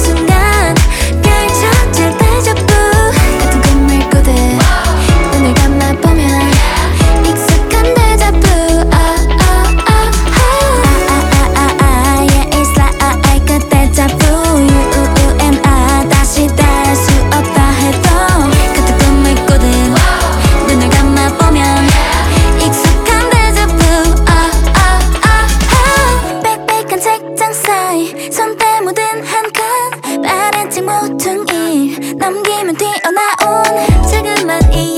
K-Pop Pop
2025-07-02 Жанр: Поп музыка Длительность